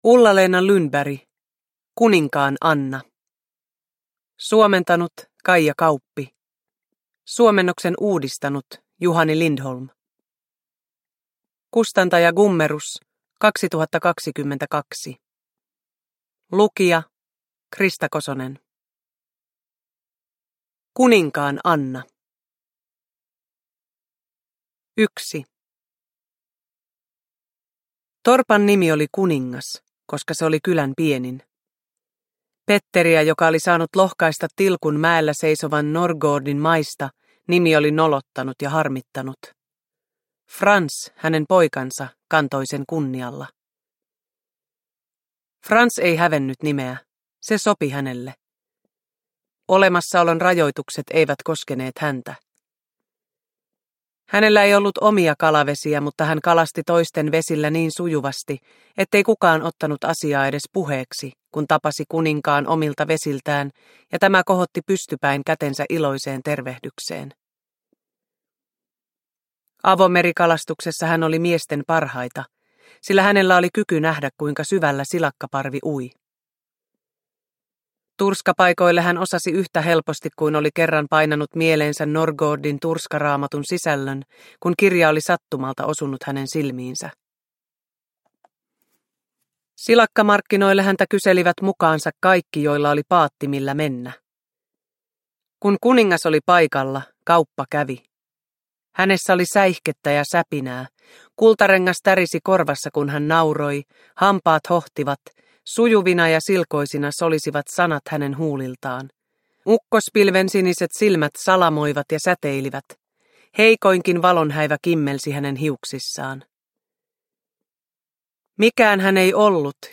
Kuninkaan Anna – Ljudbok – Laddas ner
Uppläsare: Krista Kosonen